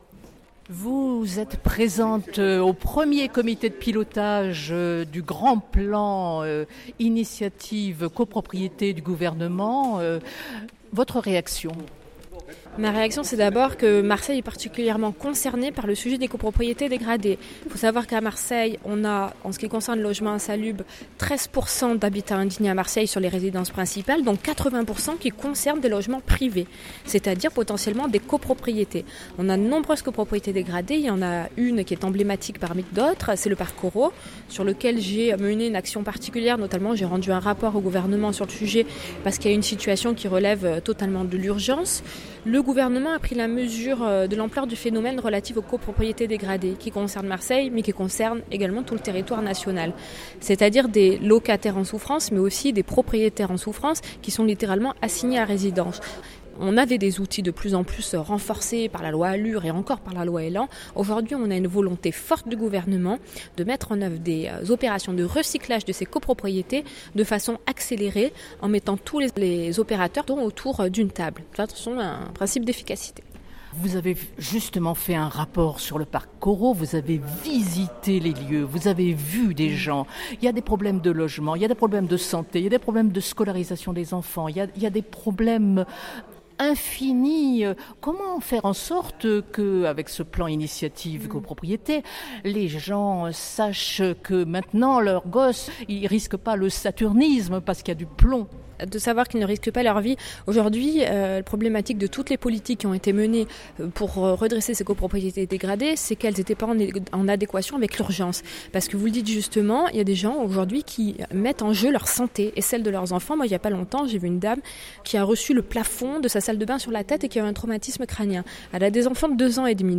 arlette_fructus_adjointe_maire_et_vice_pres_amphabitat_logement_10_10_18.mp3